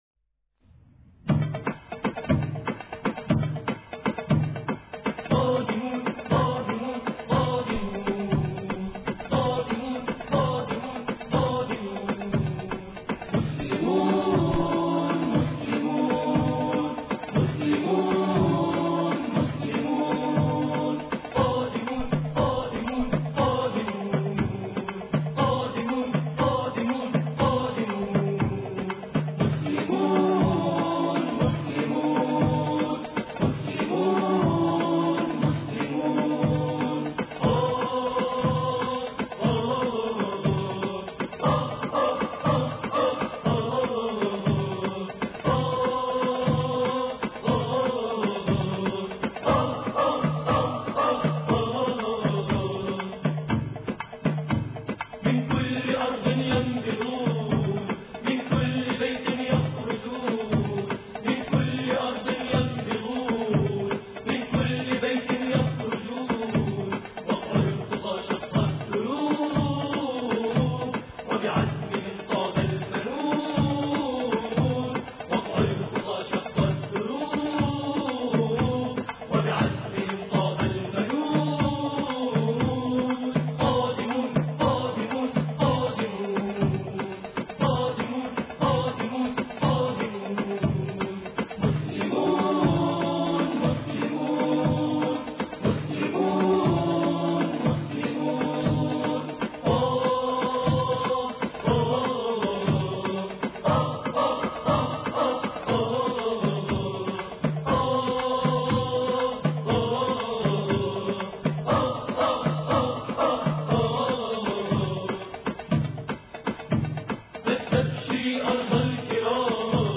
قادمون الإثنين 23 يونيو 2008 - 00:00 بتوقيت طهران تنزيل الحماسية شاركوا هذا الخبر مع أصدقائكم ذات صلة الاقصى شد الرحلة أيها السائل عني من أنا..